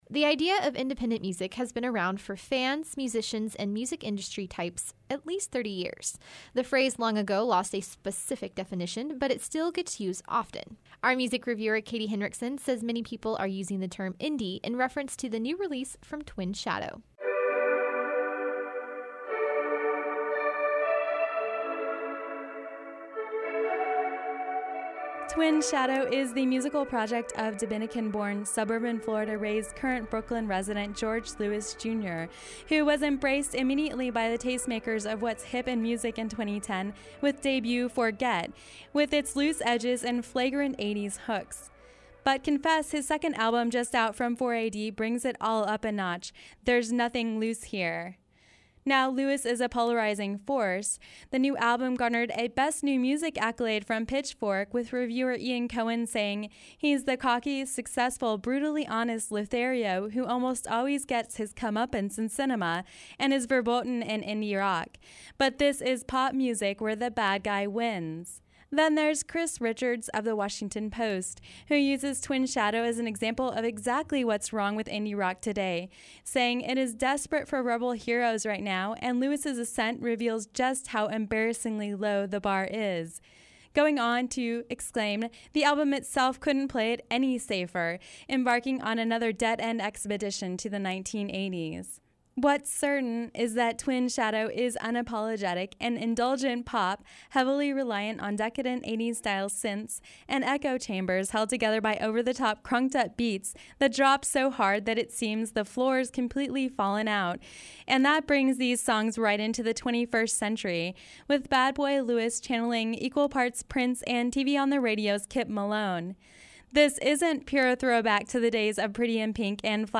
Music Review – Twin Shadow